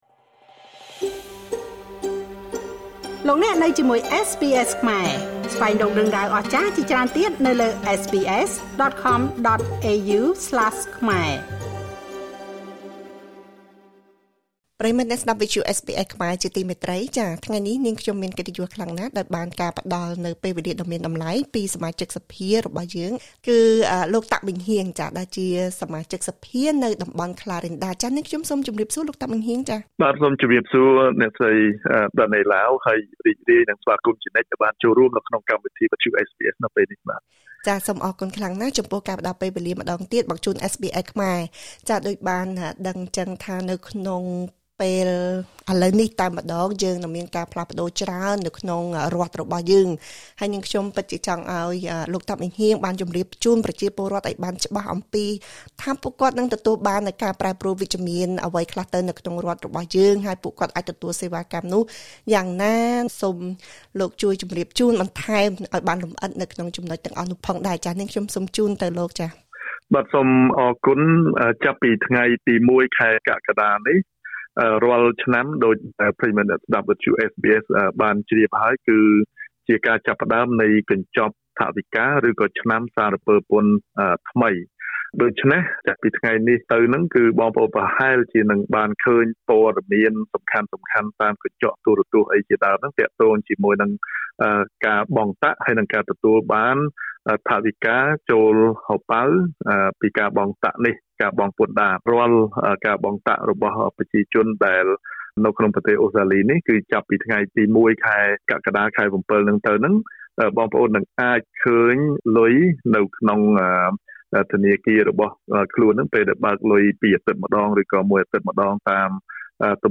សូមស្តាប់បទសម្ភាសន៍ ជាមួយលោក តាក់ ម៉េងហ៊ាង សមាជិកសភារដ្ឋវិចថូរៀ នៃតំបន់ក្លារីនដា។